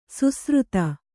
♪ susruta